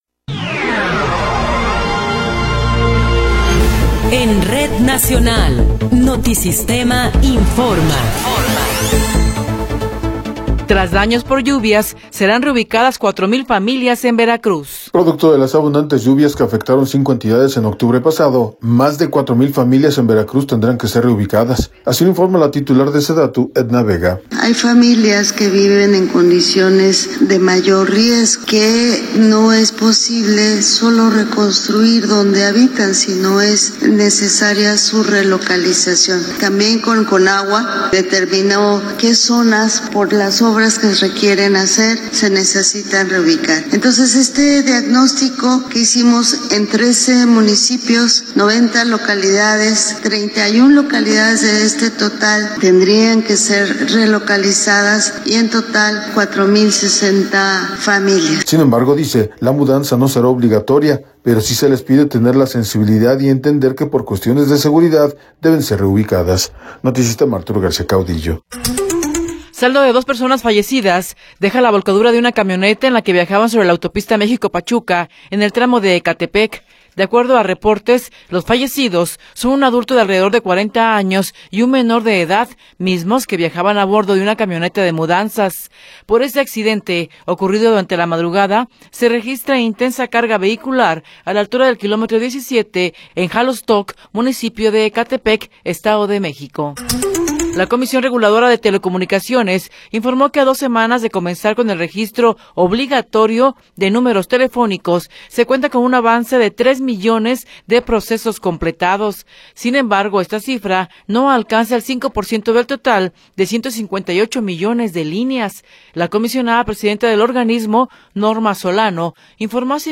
Noticiero 10 hrs. – 23 de Enero de 2026
Resumen informativo Notisistema, la mejor y más completa información cada hora en la hora.